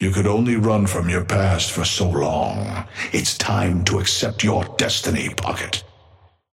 Amber Hand voice line - You could only run from your past for so long.
Patron_male_ally_synth_start_03.mp3